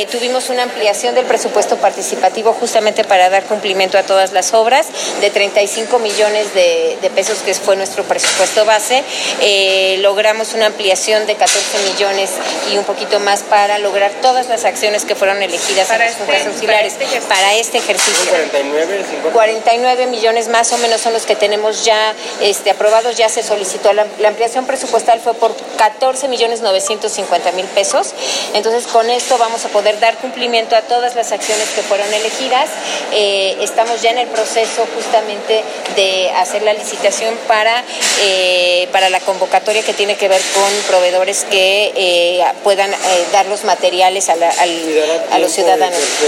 En entrevista posterior a participar en la primera muestra de “El Totonacapan Vive” en Palacio Municipal, la funcionaria abundó que se encuentran en el proceso de efectuar la licitación que estará disponible para que participen proveedores en el rubro de materiales.